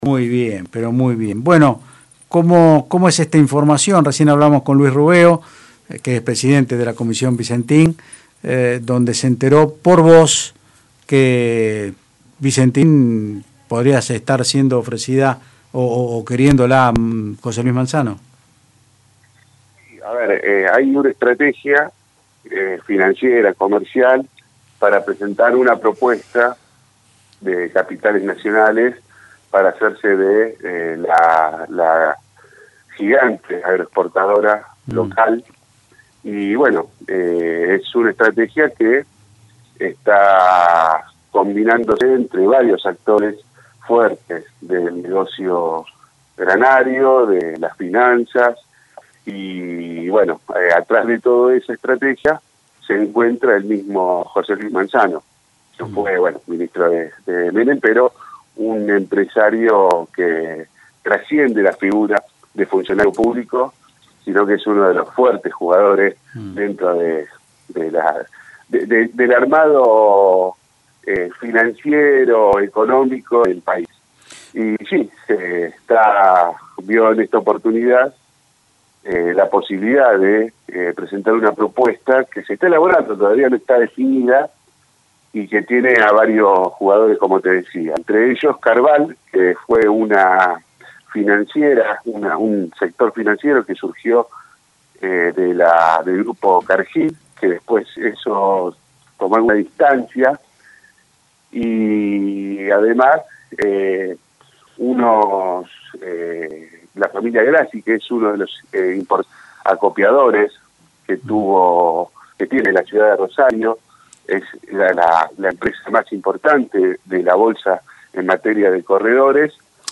explica en Otros Ámbitos (Del Plata Rosario 93.5) la nota que realizó para la políticaonline.